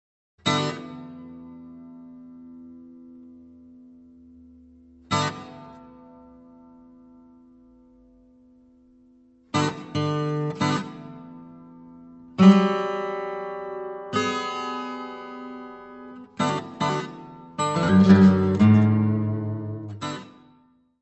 guitarras de 6 e 12 cordas.
Área:  Novas Linguagens Musicais